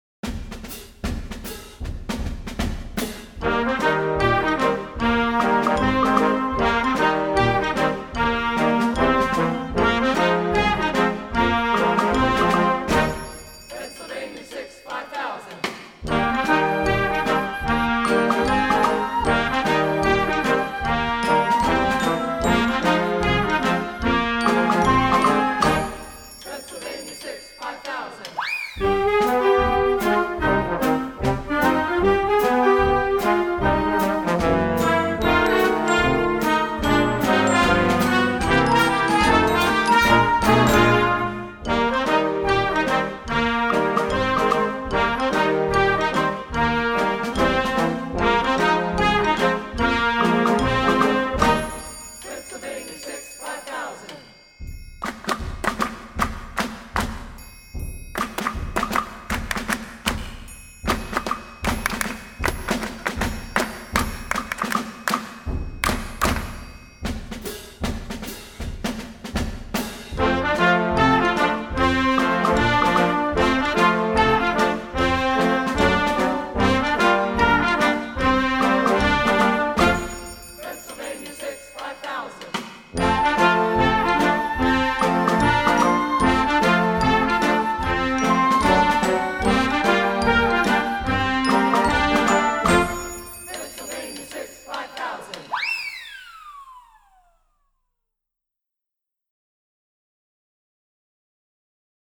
Gattung: Ausgabe für Jugendblasorchester
Besetzung: Blasorchester